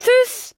Fallout 3: Audiodialoge